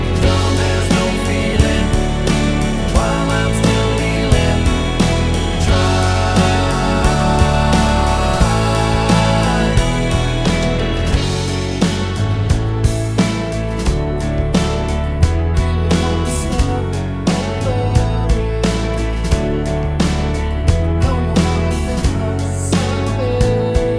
One Semitone Down Pop (1990s) 3:20 Buy £1.50